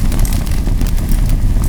fire.wav